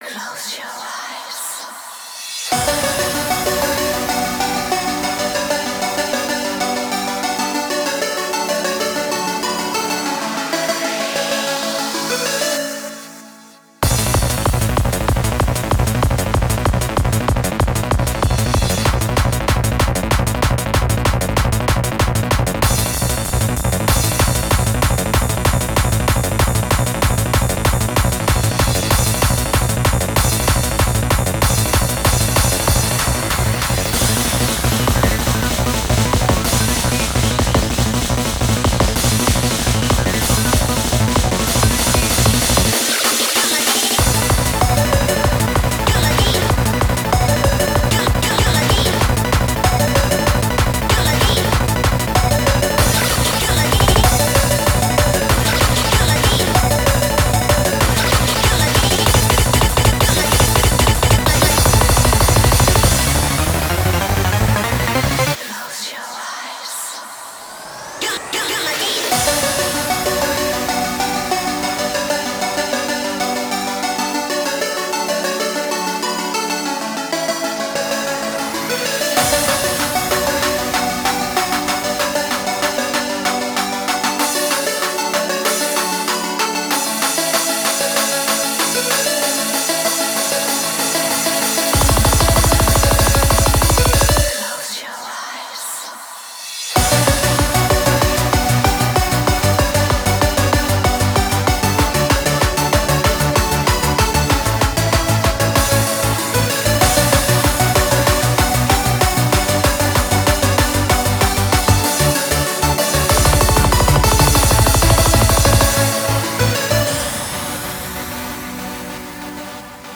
BPM191
Audio QualityPerfect (High Quality)
Genre: DANCE SPEED